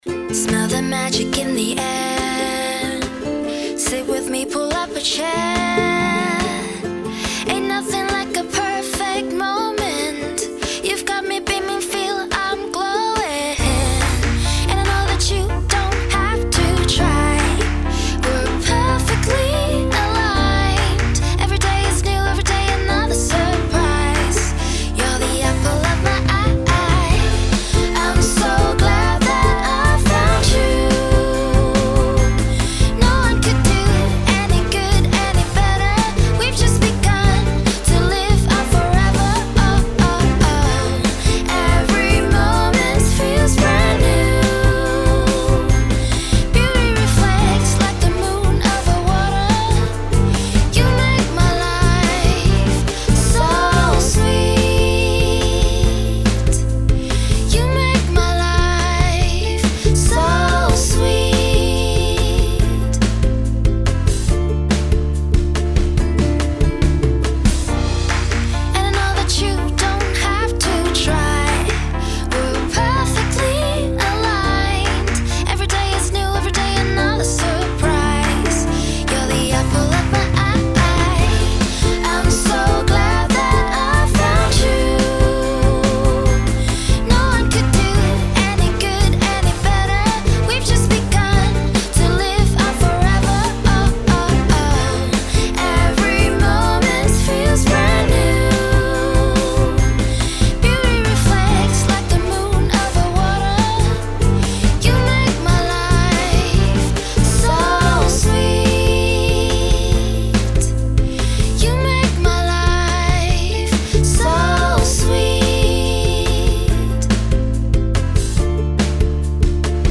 ポップで明るいムード
女性 ｱｯﾌﾟ